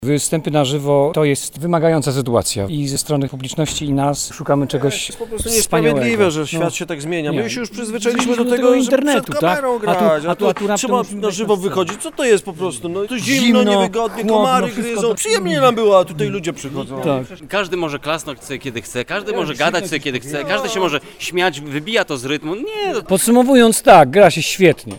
Wrażeniami na temat powrotu do występów na żywo podzielili się z nami aktorzy.